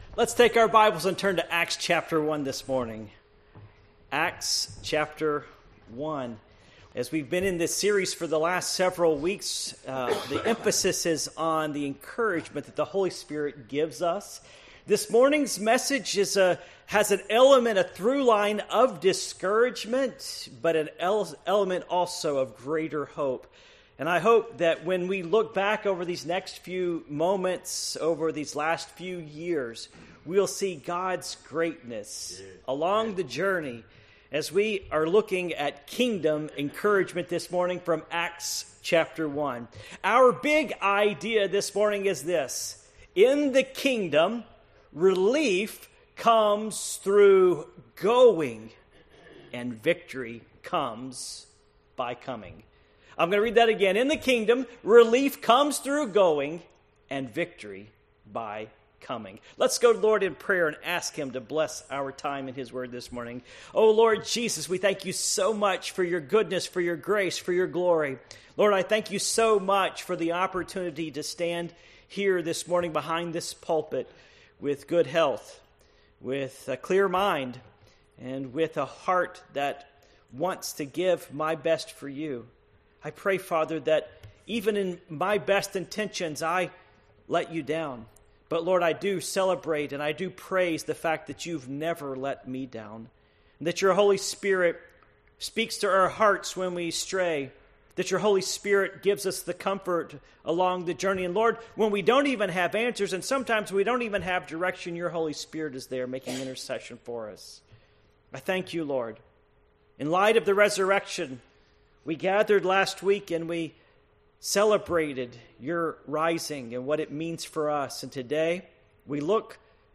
Passage: Acts 1:1-5 Service Type: Morning Worship